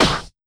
130UKSNAR2-R.wav